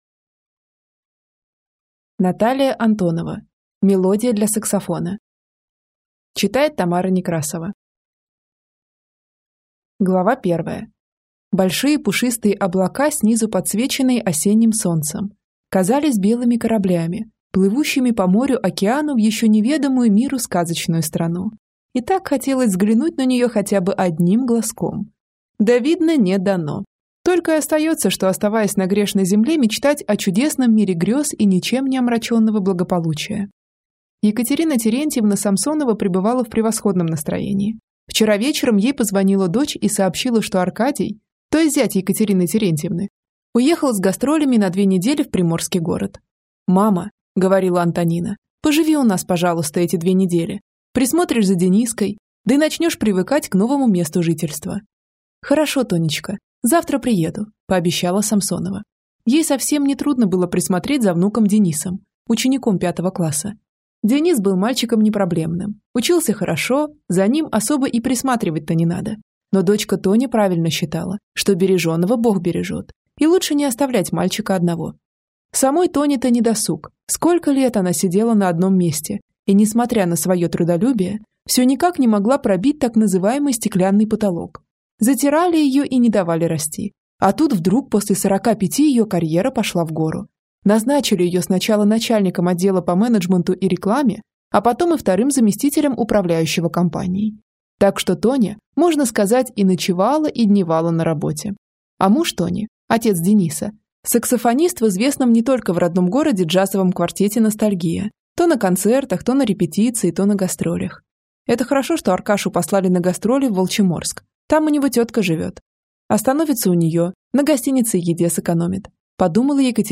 Аудиокнига Мелодия для саксофона | Библиотека аудиокниг